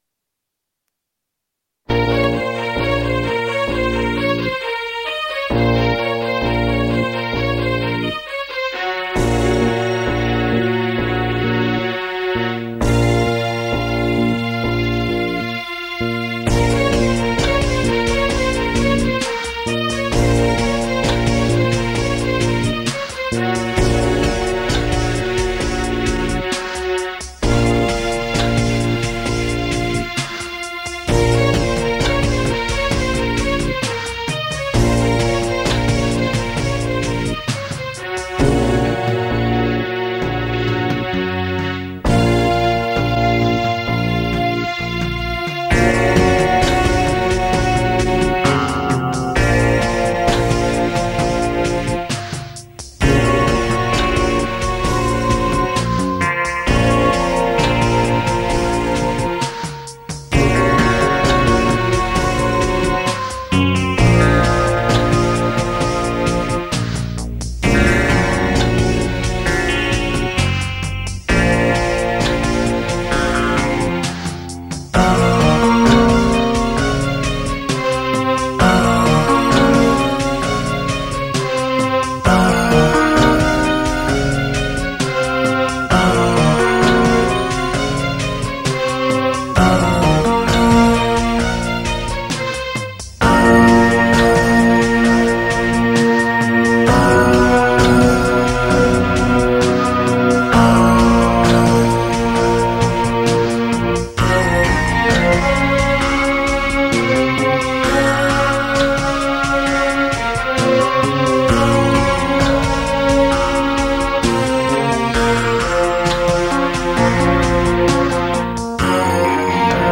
Work in electronic music experimentations
Most of my music was produced in an old Atari machine
with a midi clavier and sound software of the 90ies,